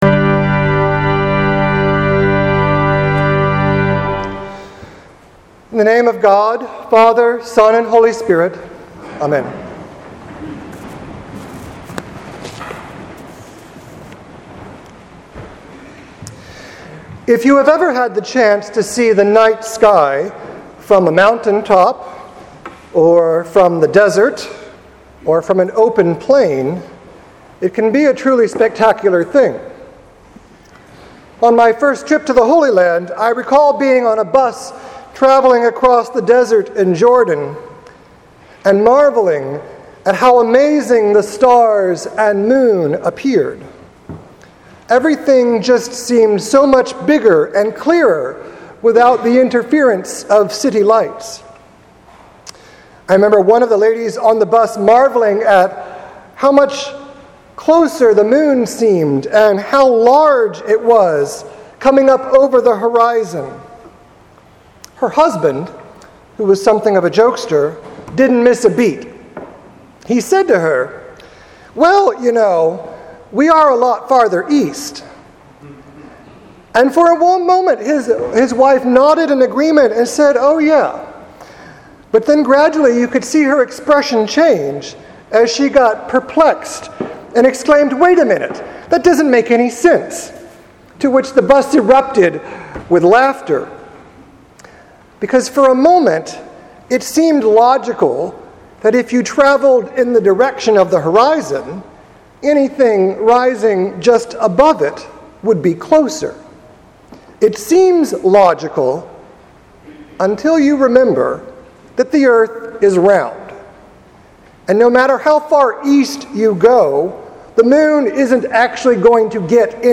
Sermon for Trinity Sunday 2017
trinity-sunday.mp3